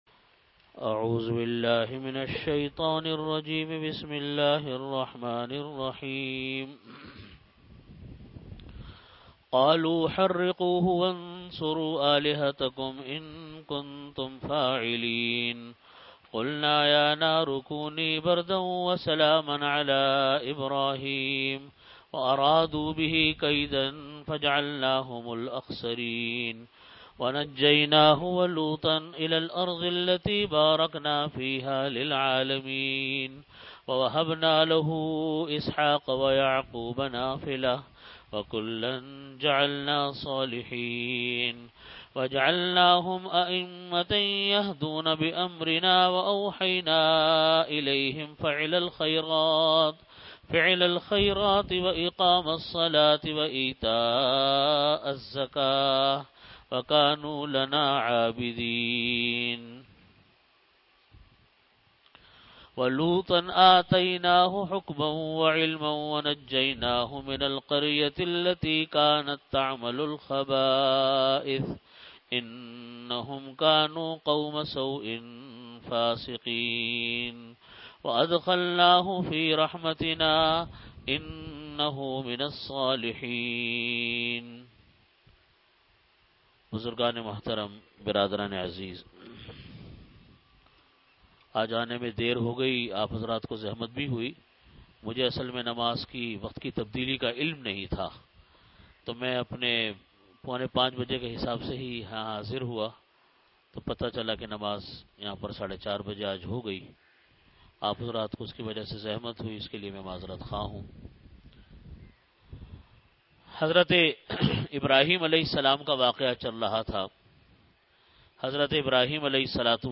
Delivered at Jamia Masjid Bait-ul-Mukkaram, Karachi.
Tafseer · Jamia Masjid Bait-ul-Mukkaram, Karachi